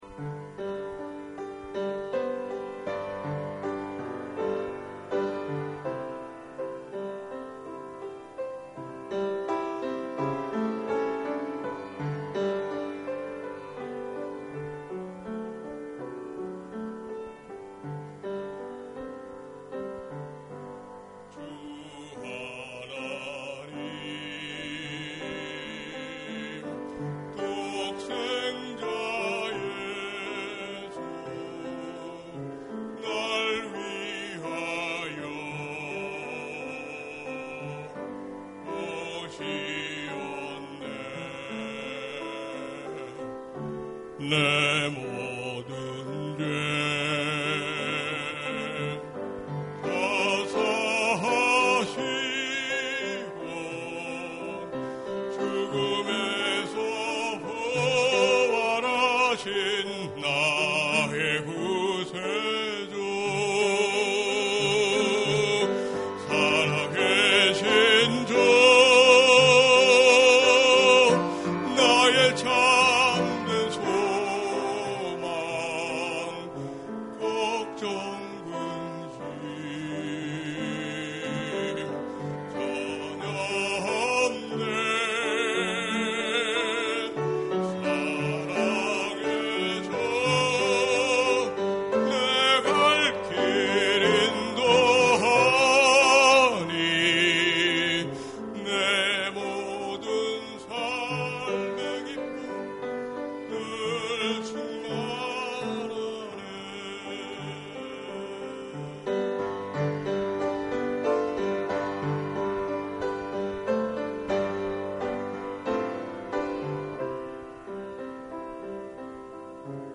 금주의찬양